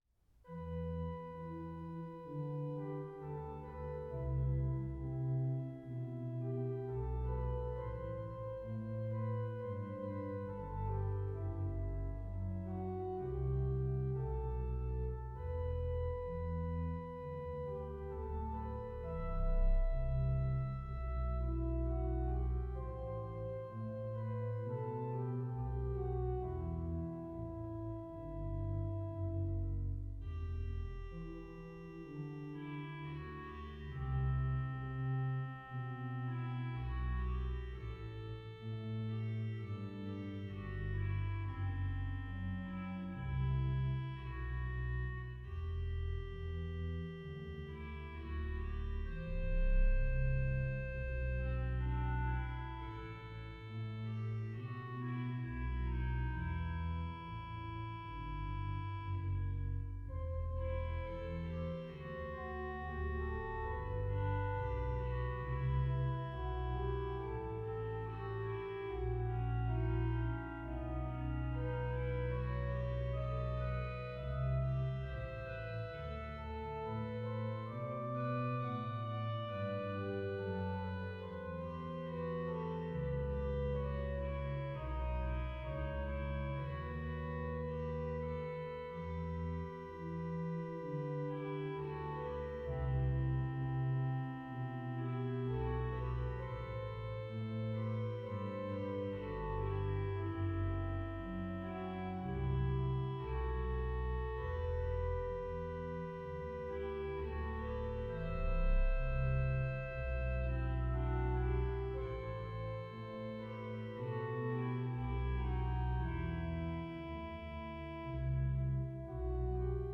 Voicing: Org 3-staff